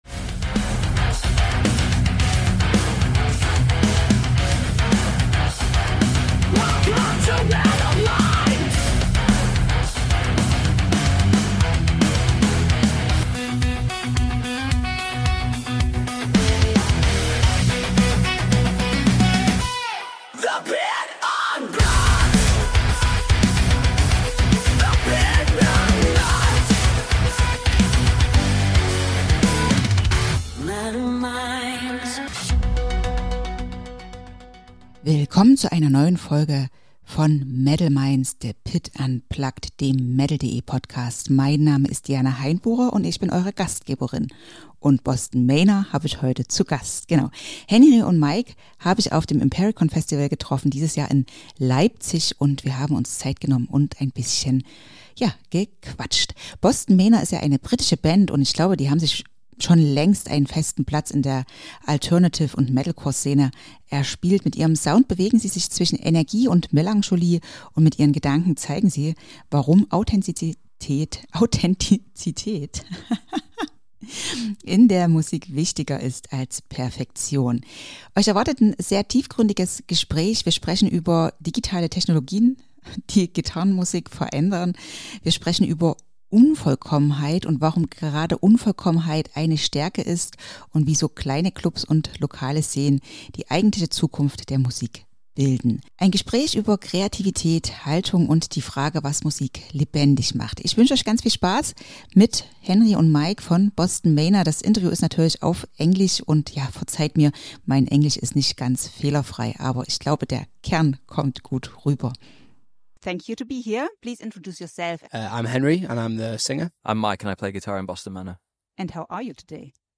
Es geht um kreative Freiheit, die Rolle von Labels, den reflektierten Einsatz von Technologie und künstlicher Intelligenz – und um die Bedeutung kleiner Clubs und lokaler Szenen als Herzstück der Musikkultur. Ein Gespräch über Werte, Visionen und die Frage, was Rockmusik heute relevant macht.